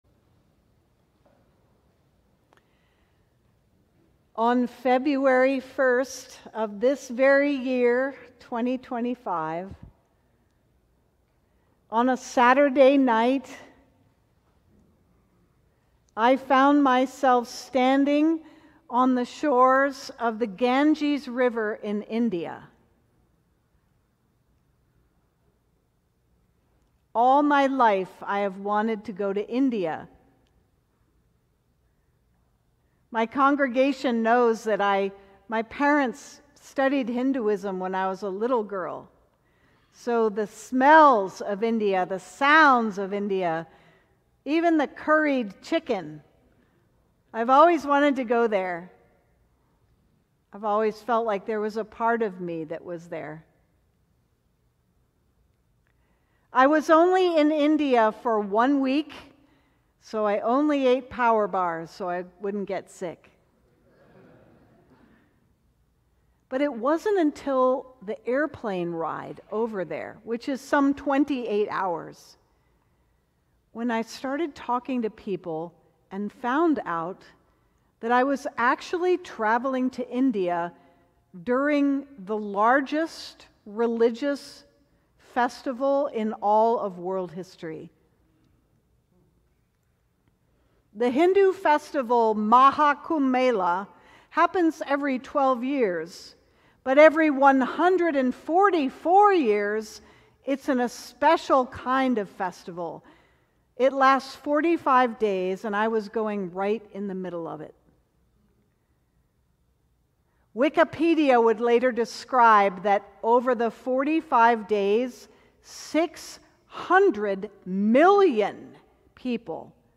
Sermon: The stars align